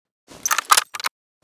sks_load.ogg